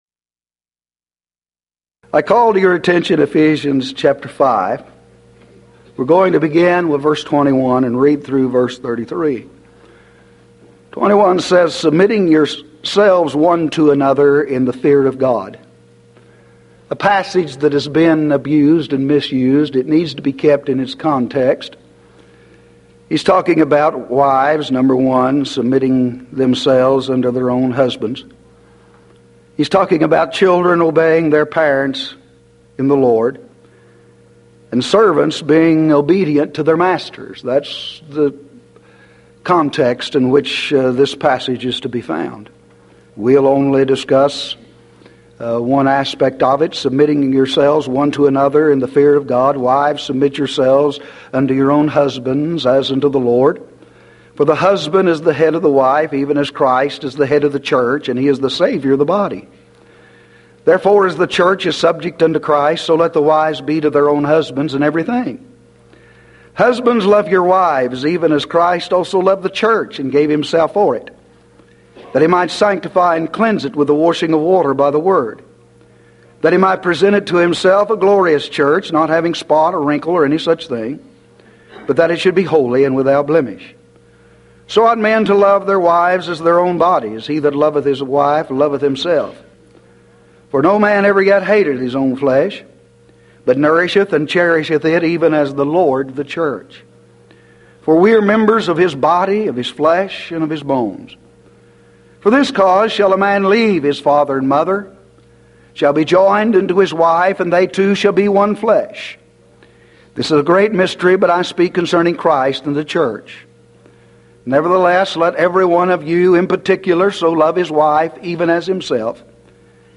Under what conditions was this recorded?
Event: 1993 Mid-West Lectures